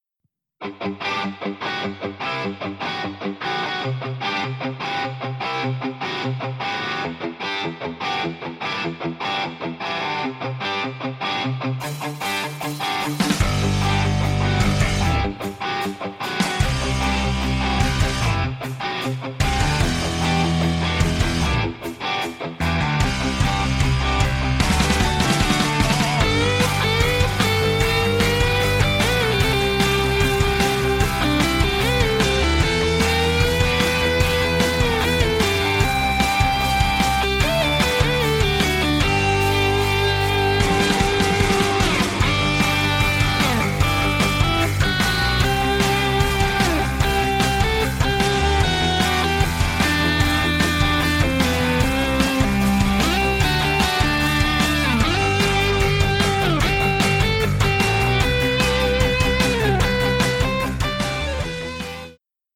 建模吉他 Studio Major7th SSG7V【WIN】v1.6.0-音频fun
它还具有内部放大器模拟器和预设，因此您可以立即表达酷炫的吉他声音。